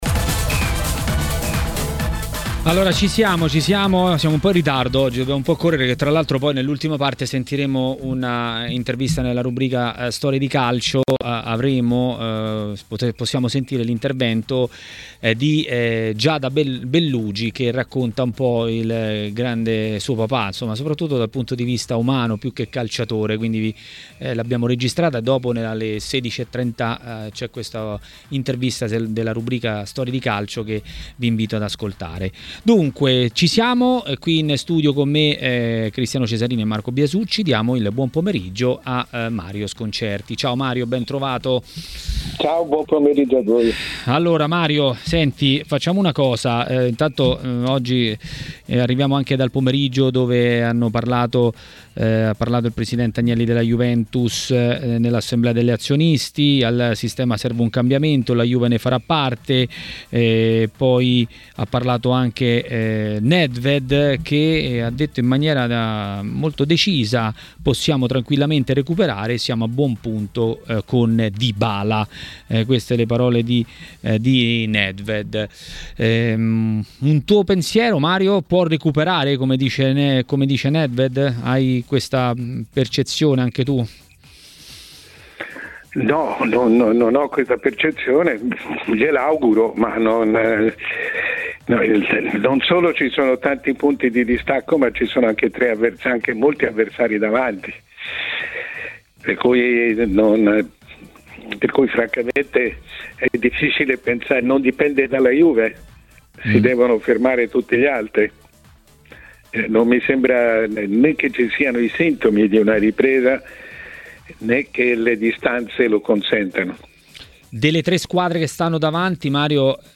A TMW Radio, durante Maracanà, è arrivato il momento del direttore Mario Sconcerti.